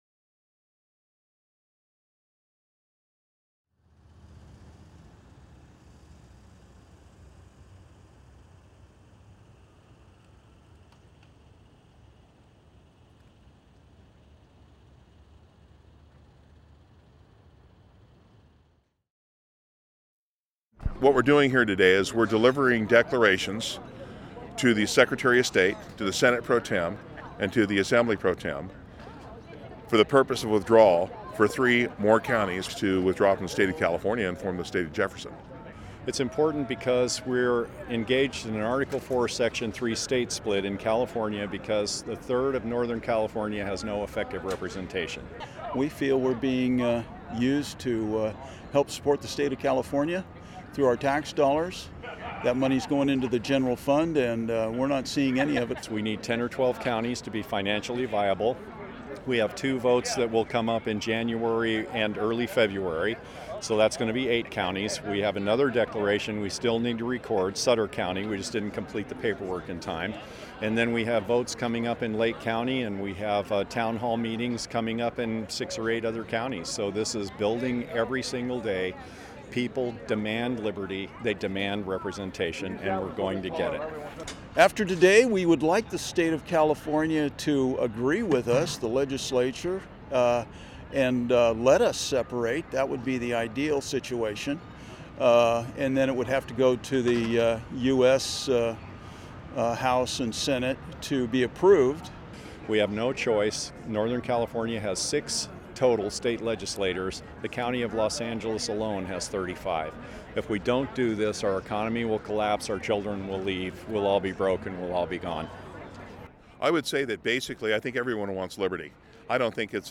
On Thursday, January 15, 2015 a small group of people gathered in front of the Califonia State Capitol in Sacramento, California. They mostly wore green and chanted slogans like "The time has come for fifty-one."